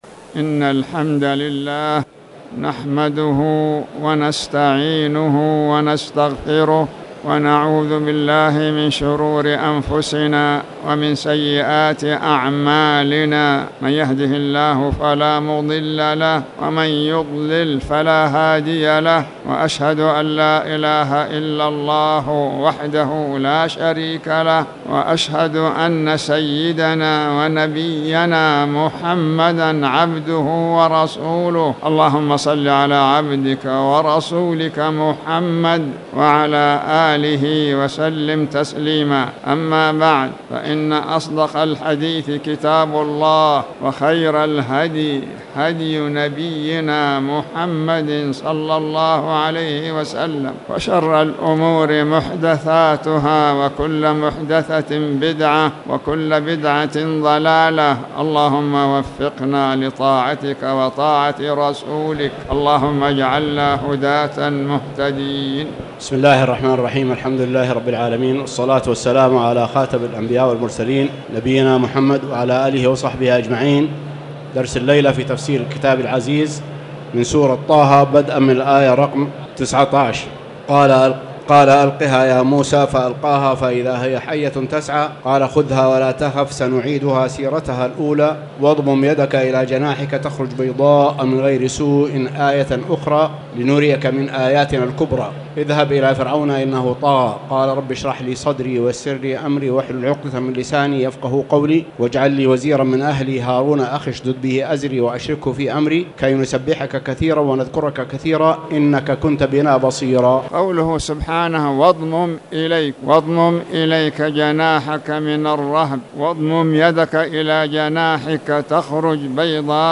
تاريخ النشر ٧ جمادى الآخرة ١٤٣٨ هـ المكان: المسجد الحرام الشيخ